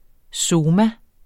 Udtale [ ˈsoːma ]